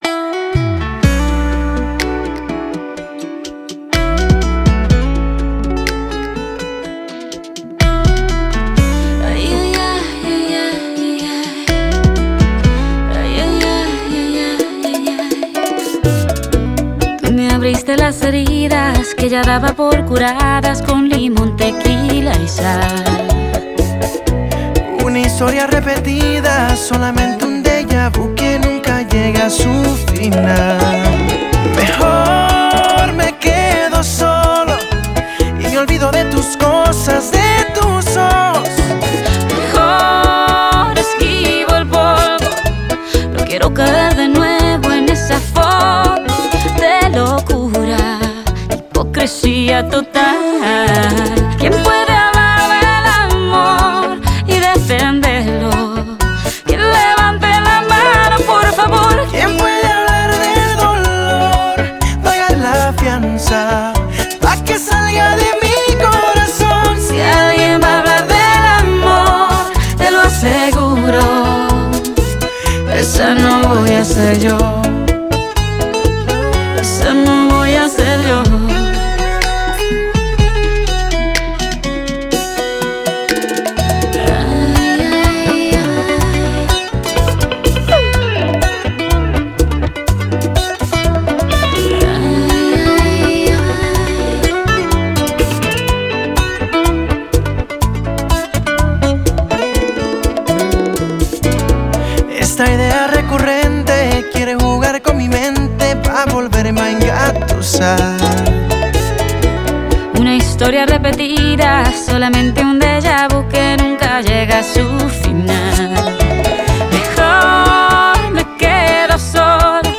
آهنگ لاتین